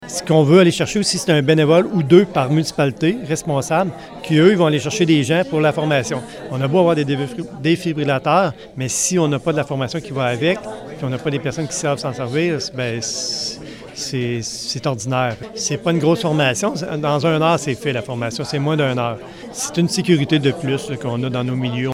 En audio, le préfet, Mario Lyonnais :